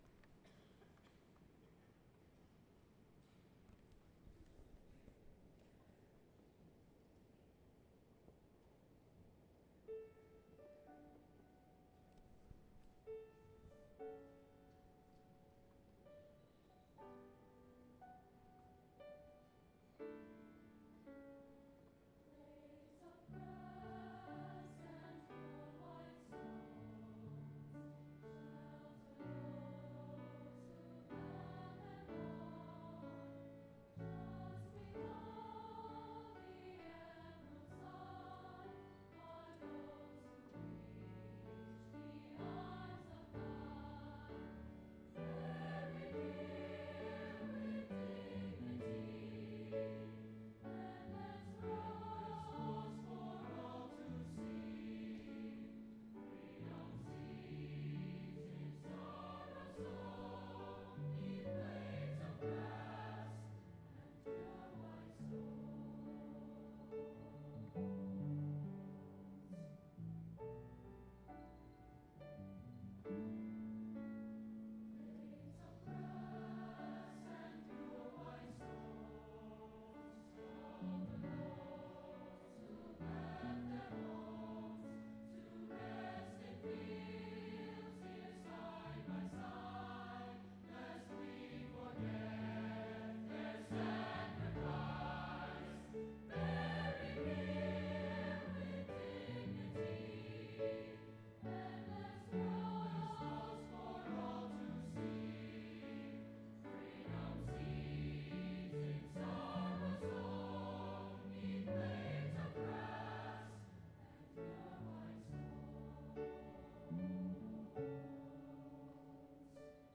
Blades of Grass and Pure White Stones as sung by the HHS Choir with Piano